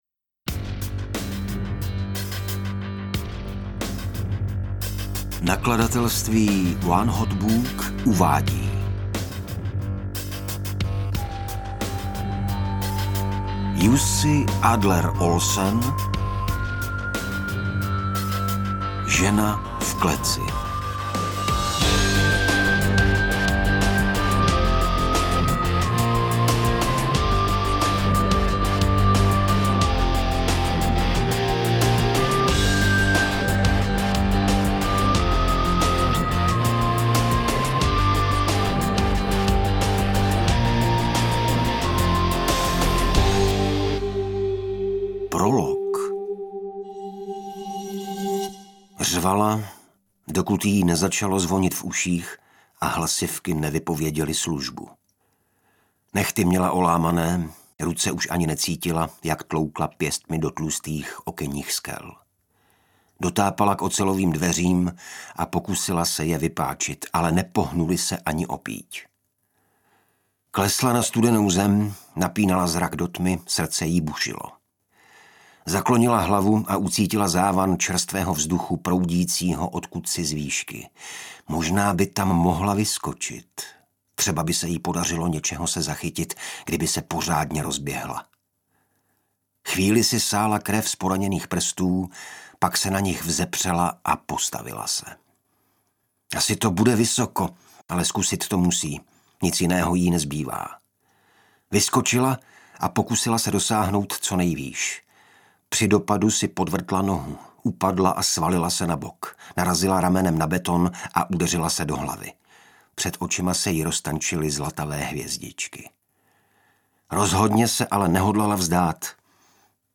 Interpret:  Igor Bareš
AudioKniha ke stažení, 40 x mp3, délka 9 hod. 22 min., velikost 522,5 MB, česky